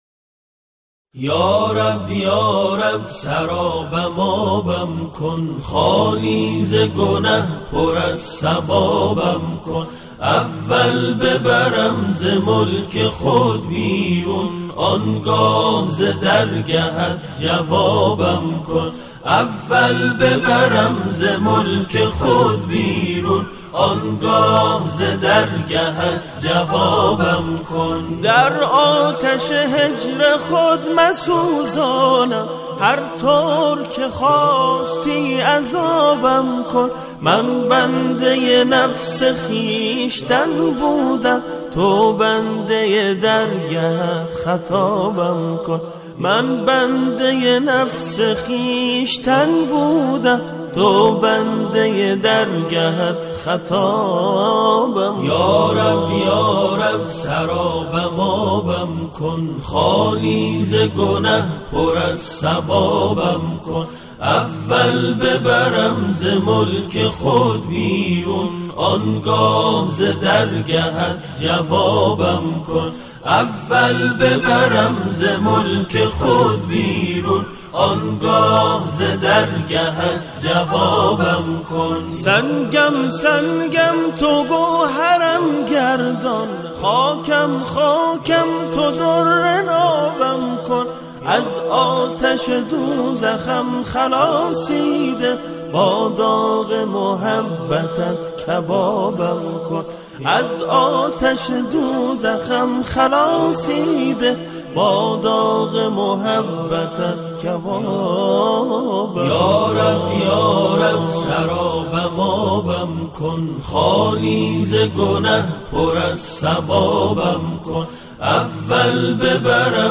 سرودهای ماه رمضان
گروهی از جمعخوانان
همخوانان، این قطعه را با شعری درباره ماه رمضان اجرا می‌کنند.